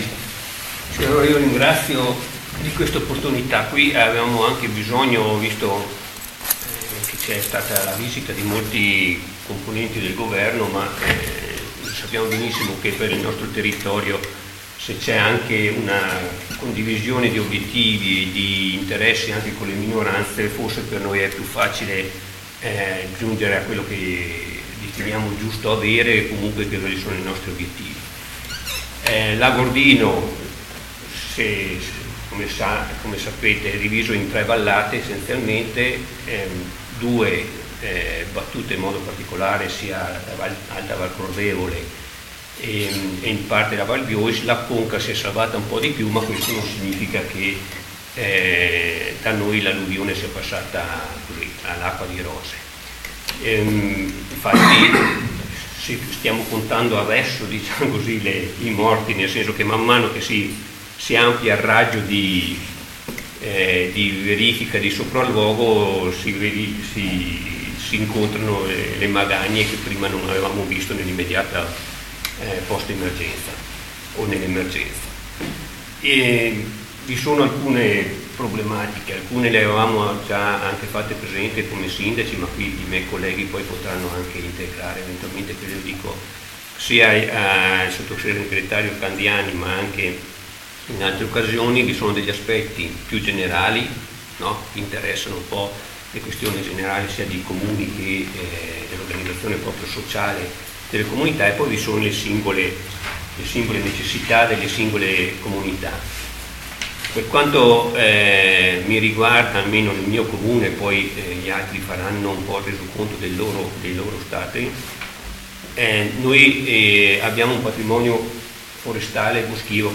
GIORNALE RADIOPIU 14 NOVEMBRE 2018